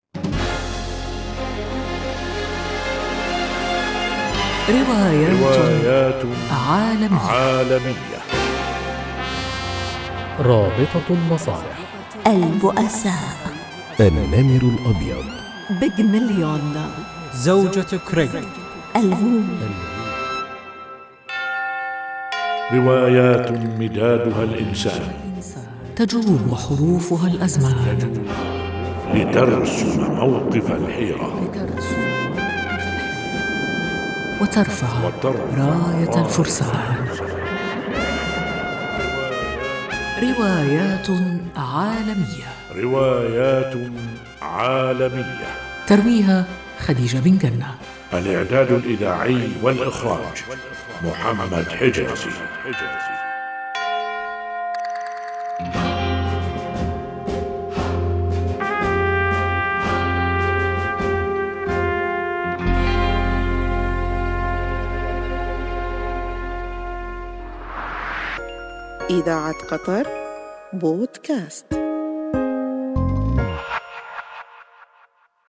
وتروي تفاصيلها من خلال مشاهد درامية بمشاركة نخبة من الممثلين، لنعيش معكم بين سطور الروايات والمسرحيات احداث درامية وانسانية مؤثرة.
ترويها: خديجة بن قنة